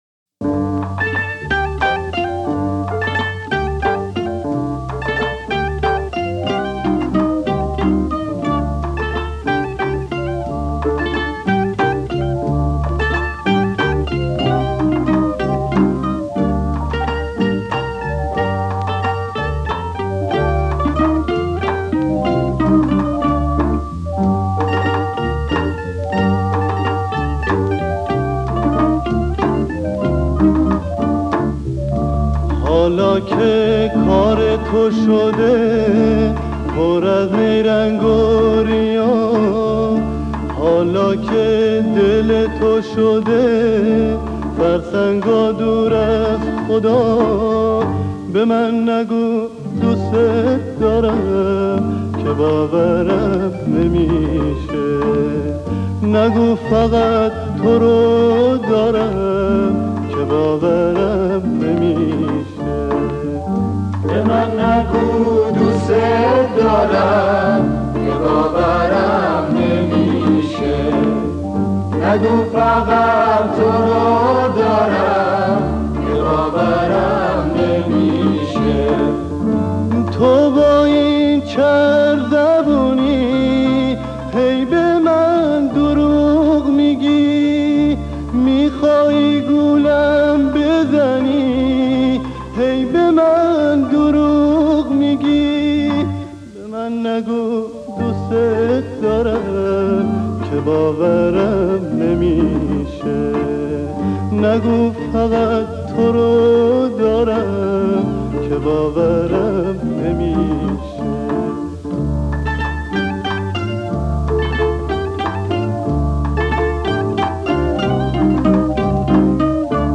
آهنگ غمگین
غم زیادی داره این آهنگ